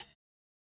MOO Closed Hat 1.wav